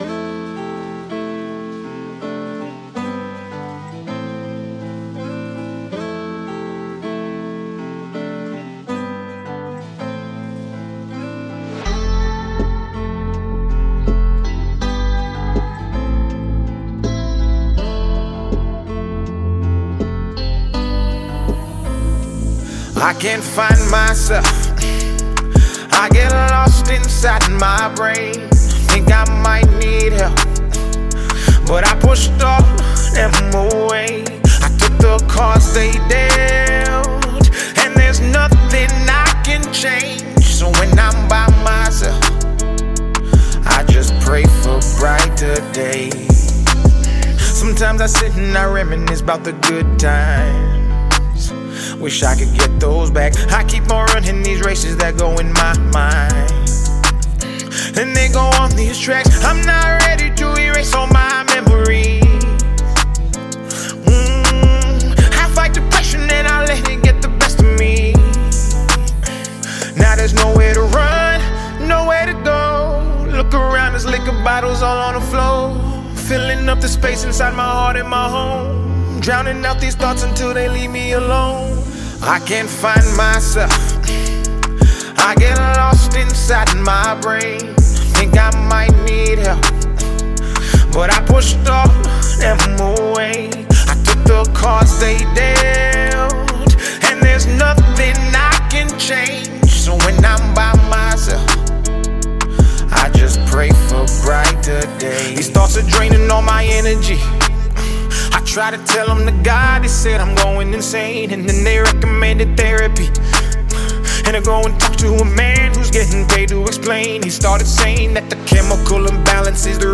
Canadian-Nigerian rapper